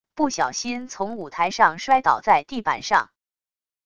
不小心从舞台上摔倒在地板上wav音频